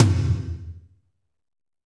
Index of /90_sSampleCDs/Northstar - Drumscapes Roland/DRM_Fast Rock/KIT_F_R Kit Wetx
TOM F RLM0LR.wav